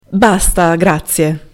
And lastly, listen how you say your prosciutto is enough to the attendant at the deli counter: